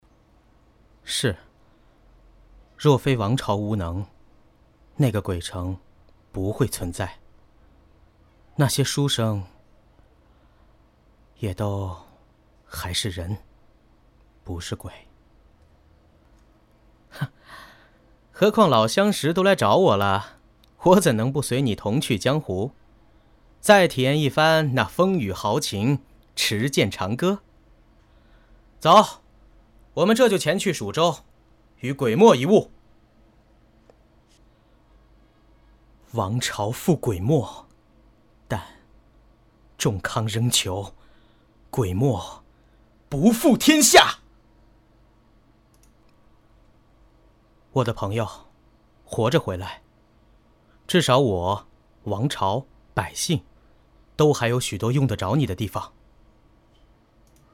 有戏磁性